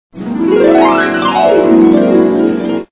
Harp
harp.wav